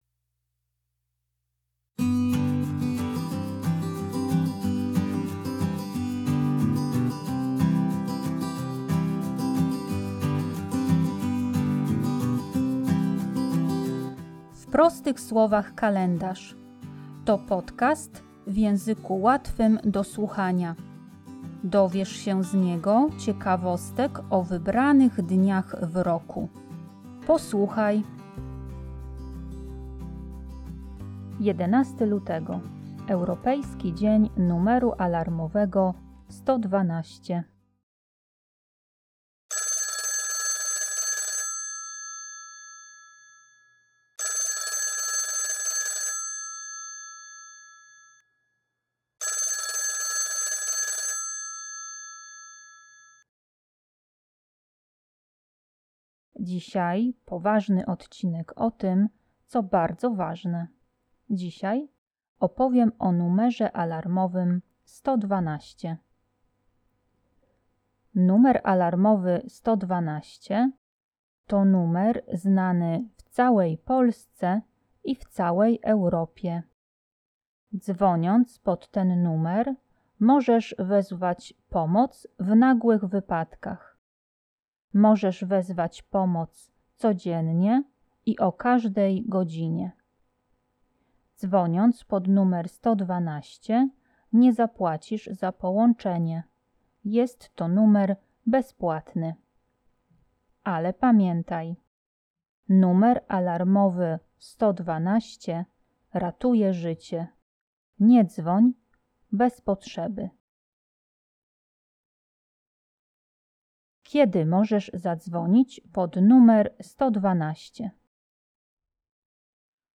W podcaście usłyszycie dzwonek telefonu.